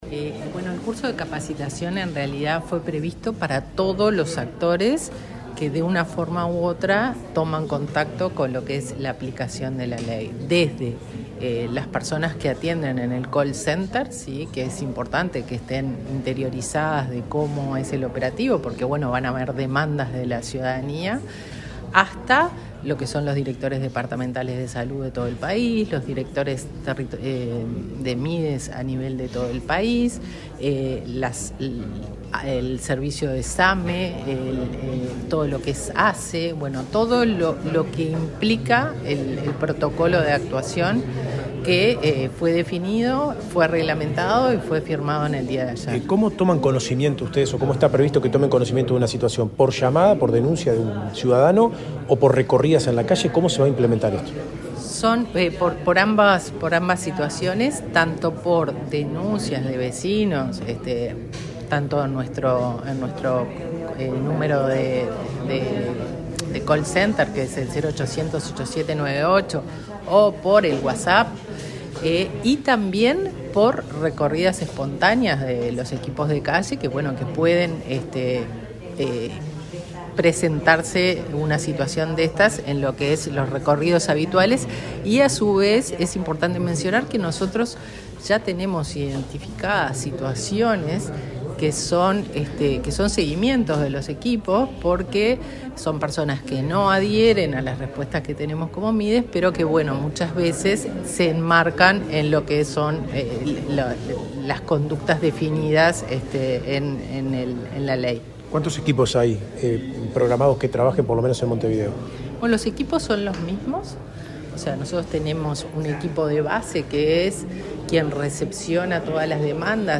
Este viernes 23 en el Ministerio de Desarrollo Social (Mides), la directora nacional de Protección Social, Fernanda Auersperg, dialogó con la prensa, luego de participar en una jornada de capacitación a los equipos técnicos que intervendrán en la implementación de la Ley de Prestación de Asistencia Obligatoria por parte del Estado.